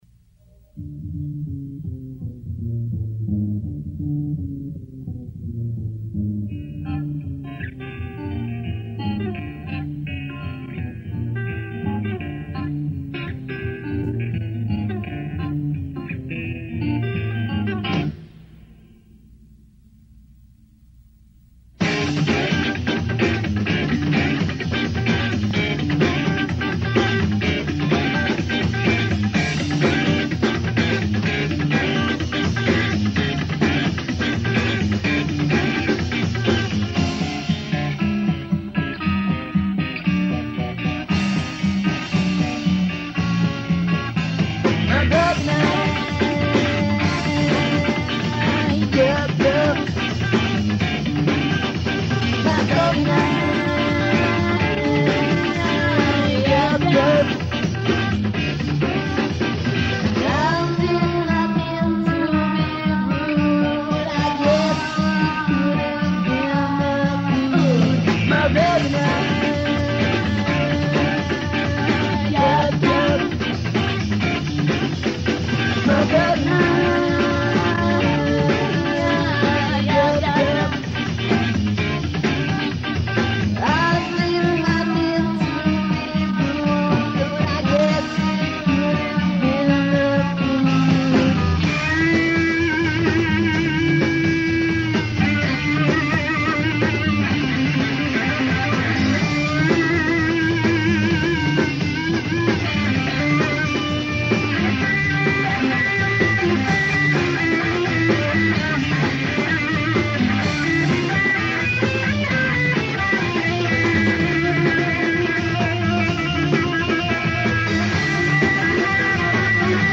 DEMO RECORDINGS